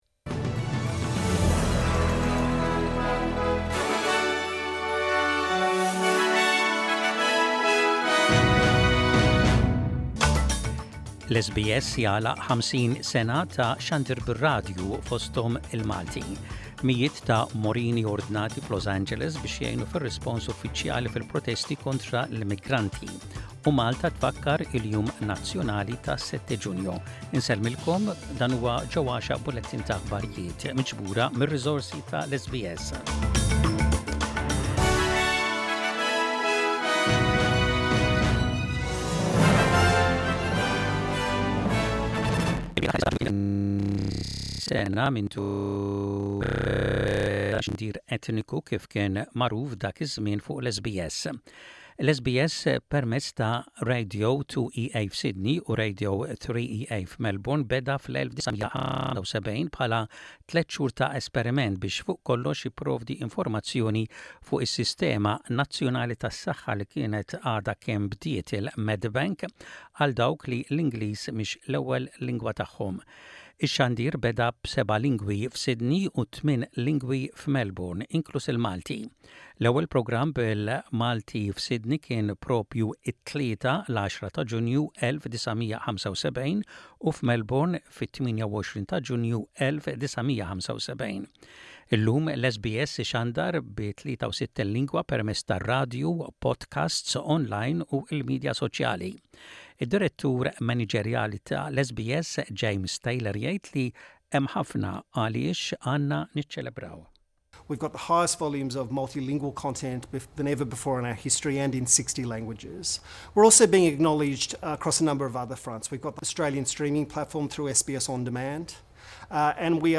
Aħbarijiet bil-Malti: 10.06.25